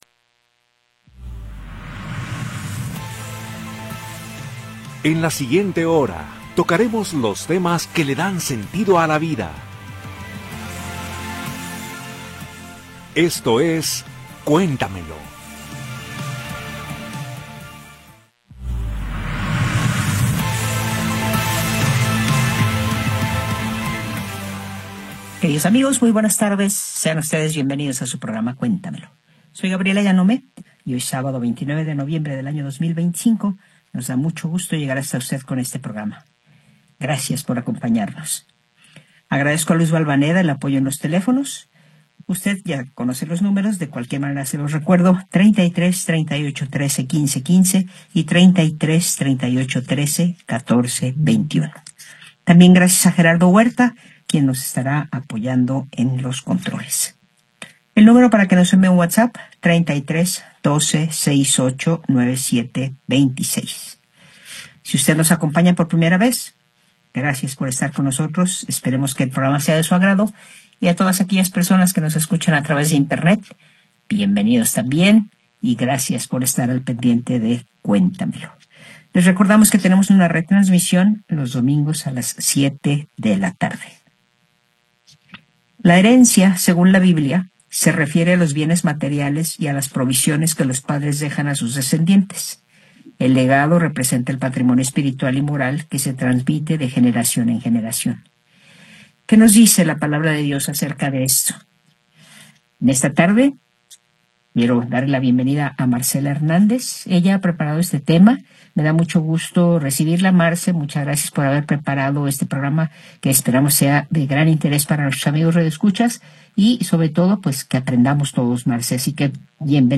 en vivo con los temas que dan sentido a la vida.